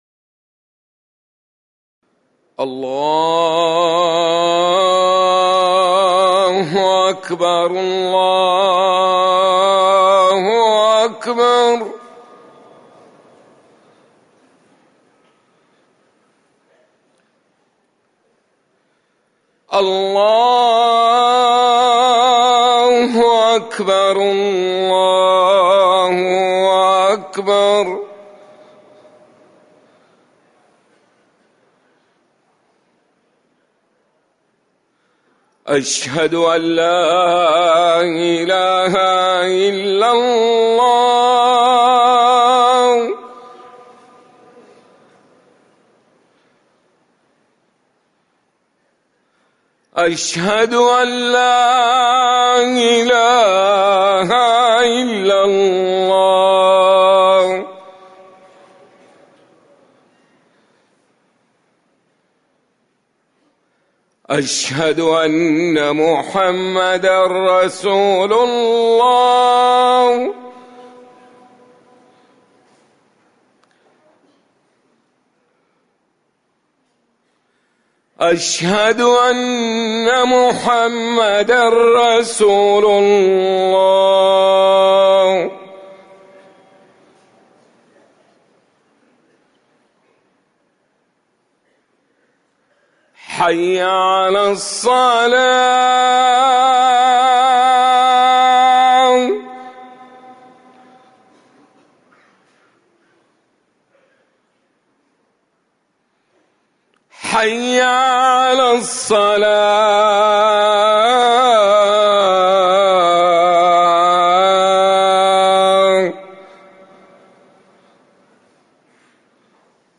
أذان المغرب
تاريخ النشر ١٦ صفر ١٤٤١ هـ المكان: المسجد النبوي الشيخ